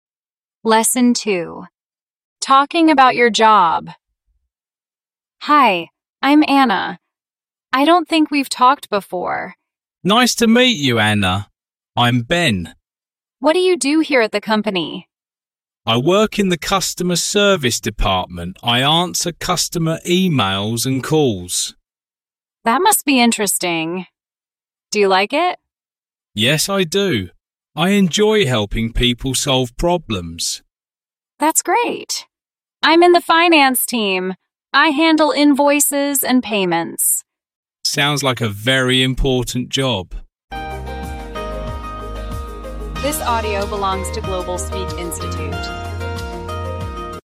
Giọng chậm